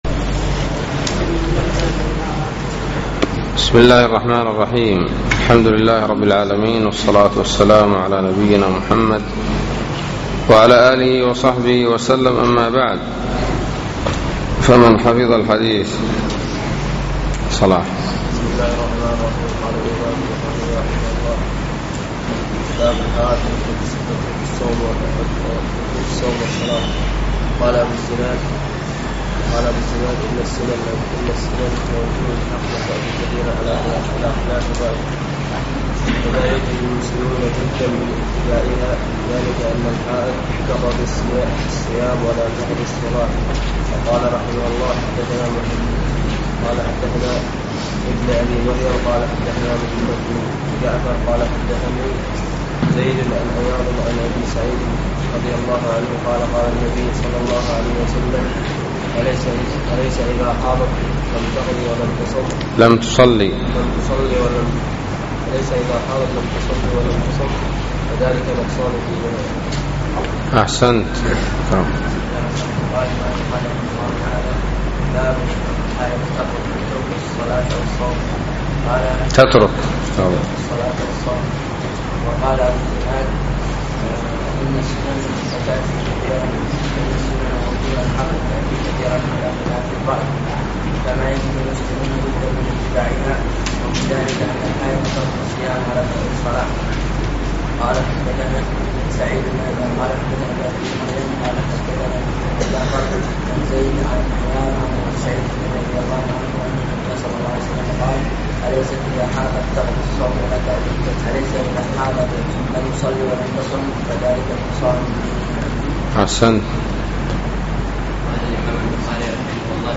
الدرس التاسع والعشرون : باب من مات وعليه صوم